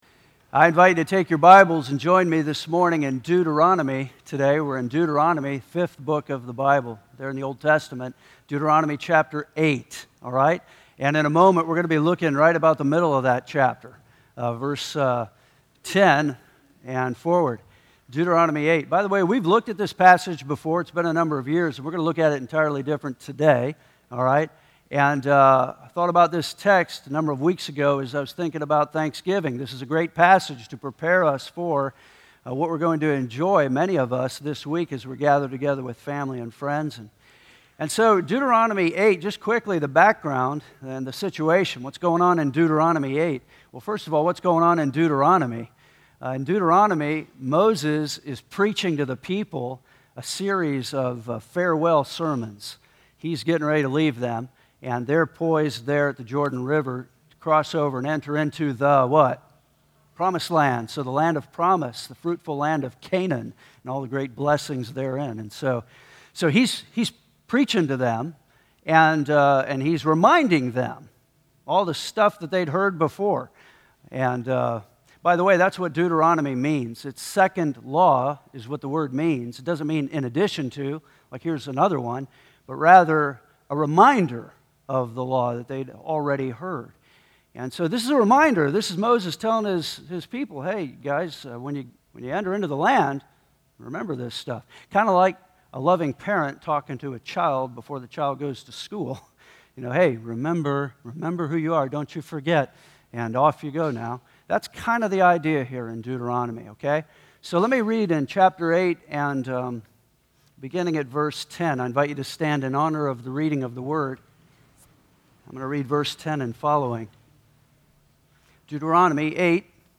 Thanksgiving Message